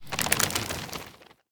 Minecraft Version Minecraft Version 1.21.5 Latest Release | Latest Snapshot 1.21.5 / assets / minecraft / sounds / mob / creaking / creaking_idle2.ogg Compare With Compare With Latest Release | Latest Snapshot
creaking_idle2.ogg